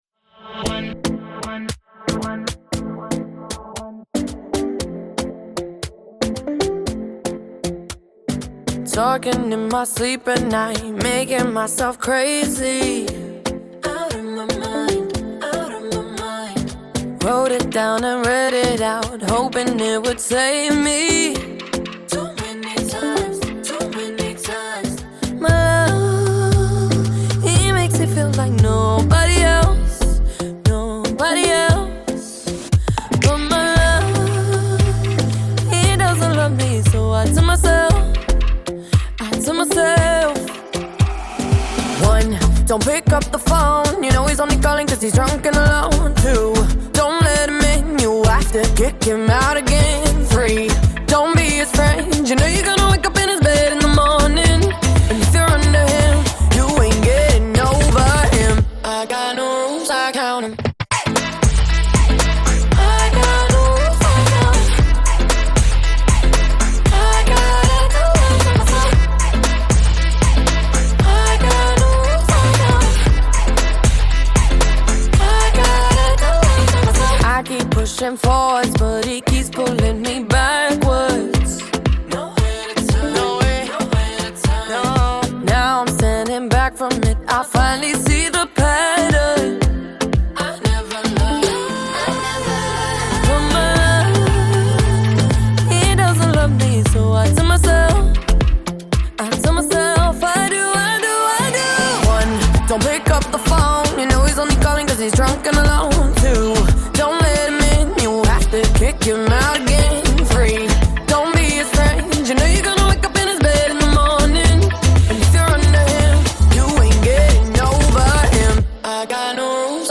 stands as one of the defining pop anthems of the late 2010s.